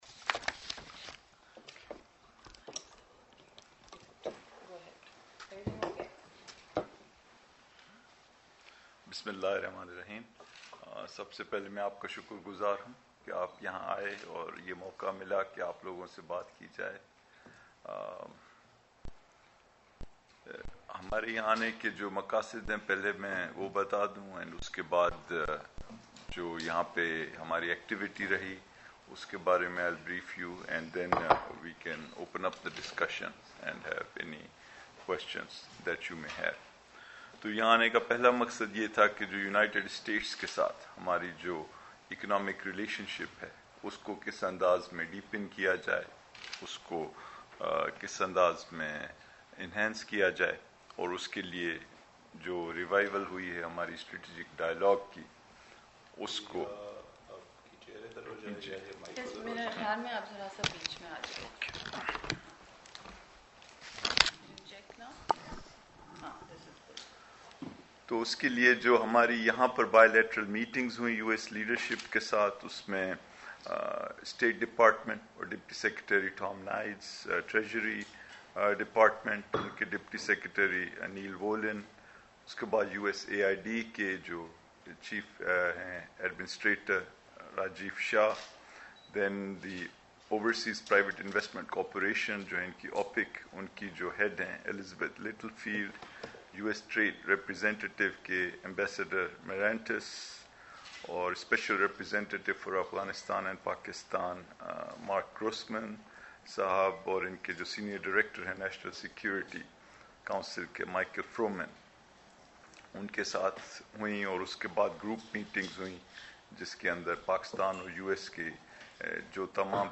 وزیر خزانہ ڈاکٹر حفیظ شیخ کی اخباری کانفرنس